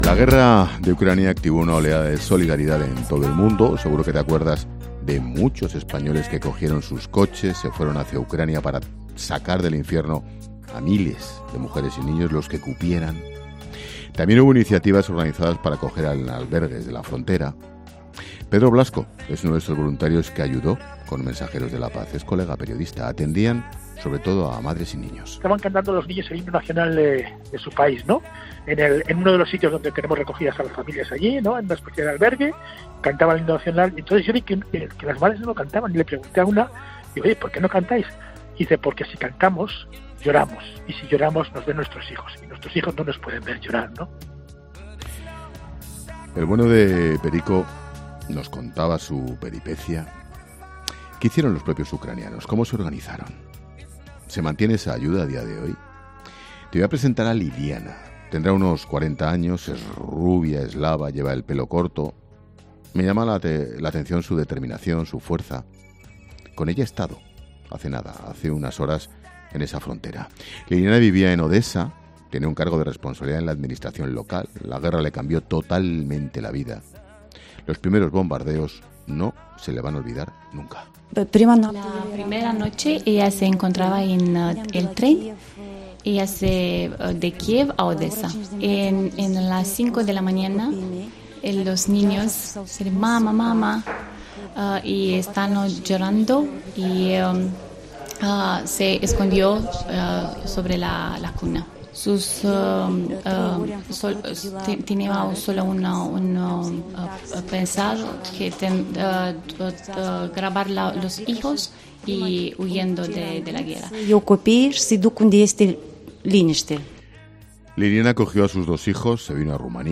La Linterna de COPE habla con una mujer ucraniana que comenzó mandando bolsas de comida a las zonas bombardeadas y ahora envía, cada día, camiones con ayuda desde Rumanía